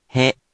We’re going to show you the character, then you you can click the play button to hear QUIZBO™ sound it out for you.
In romaji, 「へ」 is transliterated as 「he」which sounds like 「hay」